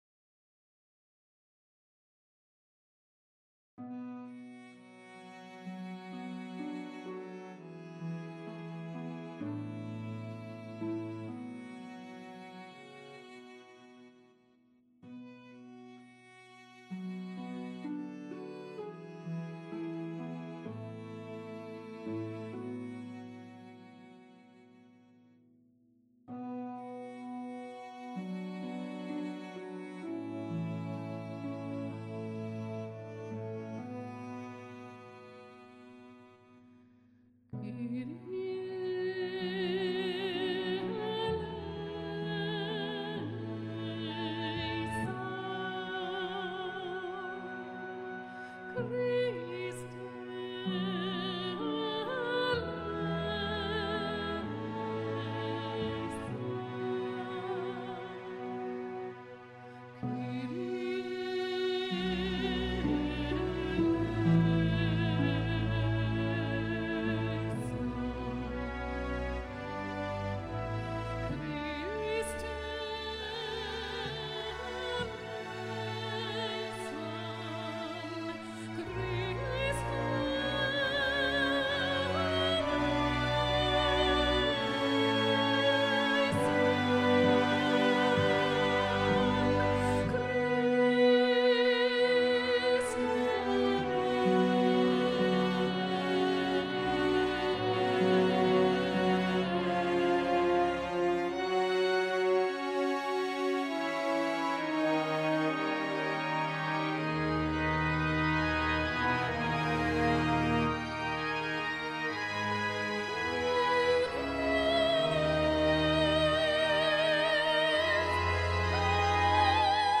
Meditationsgesang